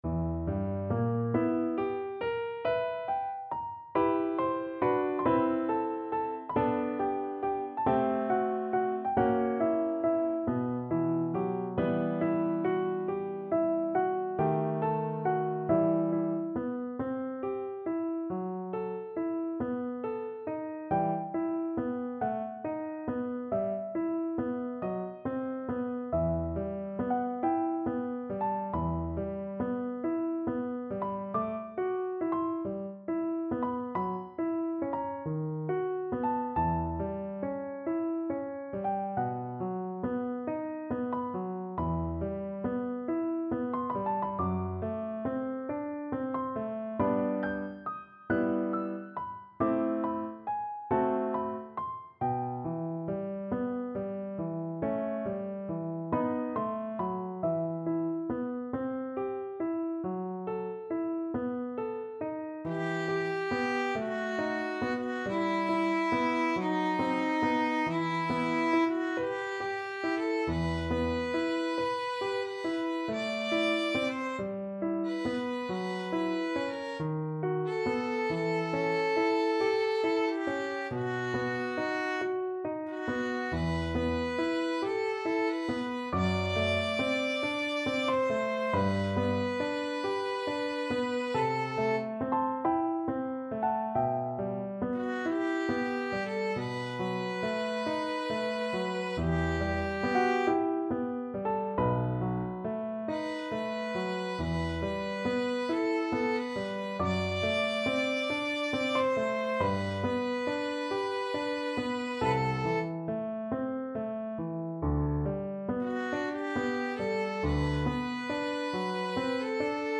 Violin version
Slow =c.46
4/4 (View more 4/4 Music)
Violin  (View more Advanced Violin Music)
Classical (View more Classical Violin Music)